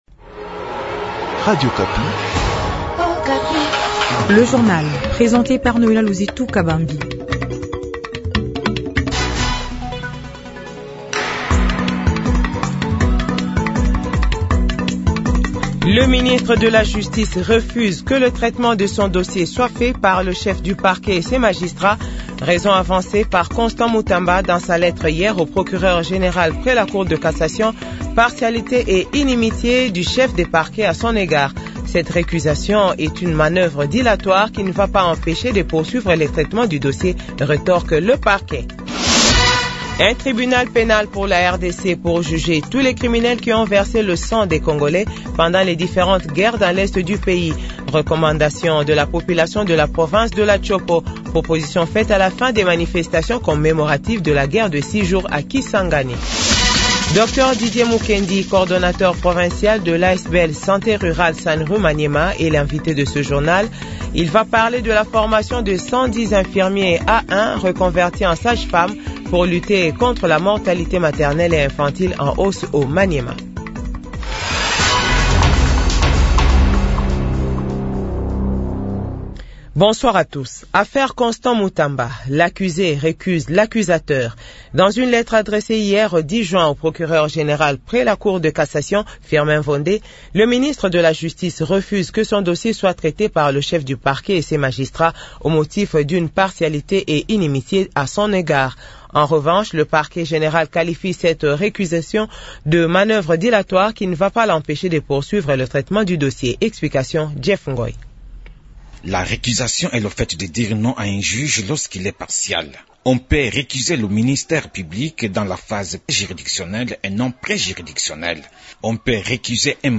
Journal 18h